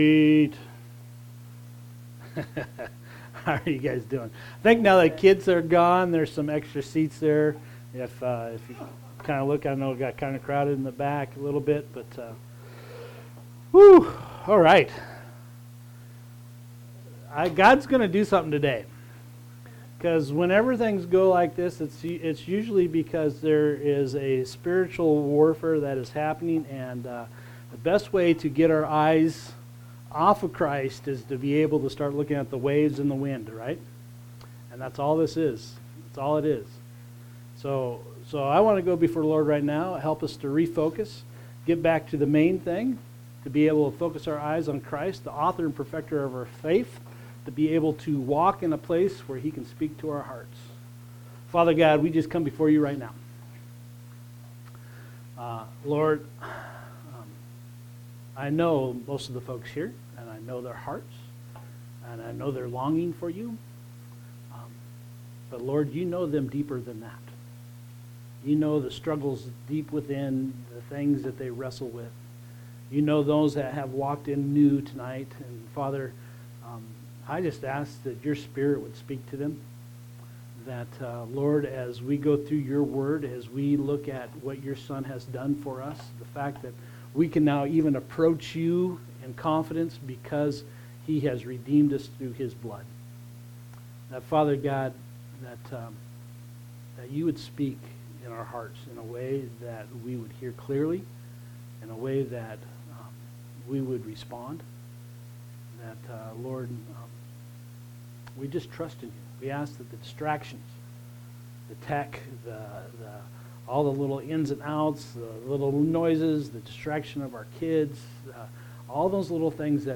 Unlocking the Parables of Jesus Current Sermon